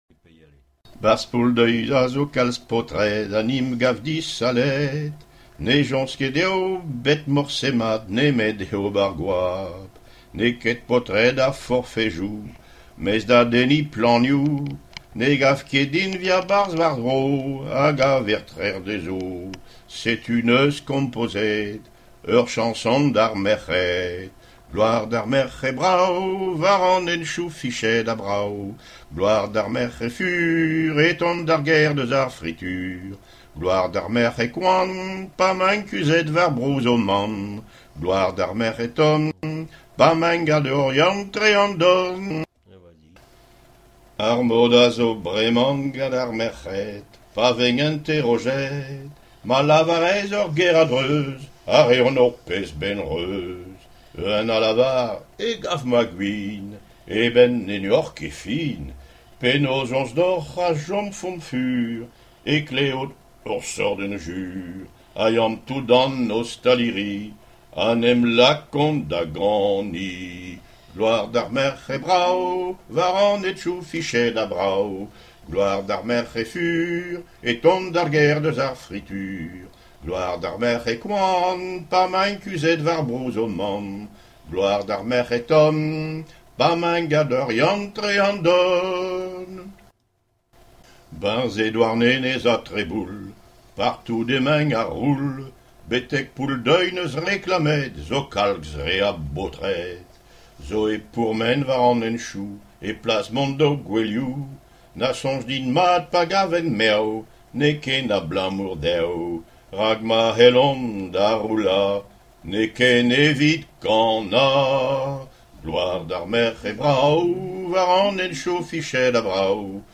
Genre strophique
Témoignages et chansons
Catégorie Pièce musicale inédite